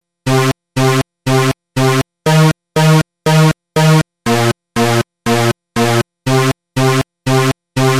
标签： 120 bpm Electro Loops Synth Loops 1.35 MB wav Key : Unknown
声道立体声